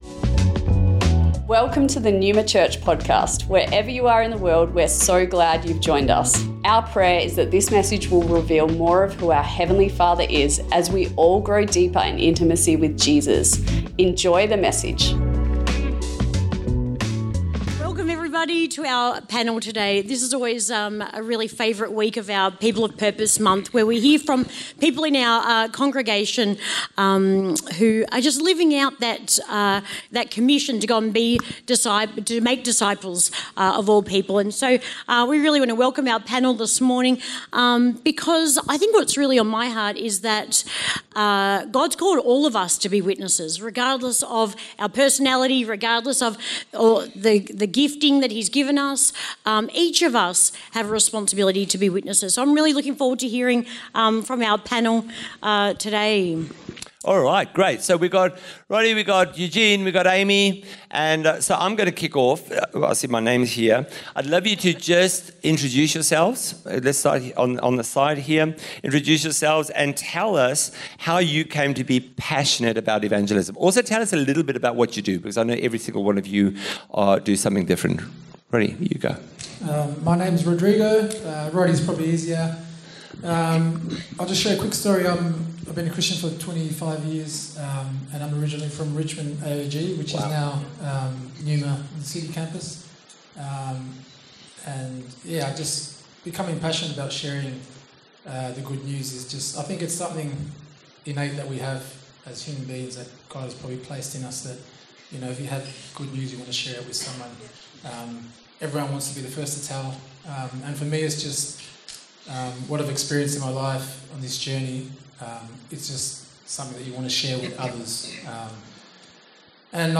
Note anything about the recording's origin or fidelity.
People of Purpose - Panel Interview | Neuma Church Melbourne East Originally recorded on Sunday 22th March | 9AM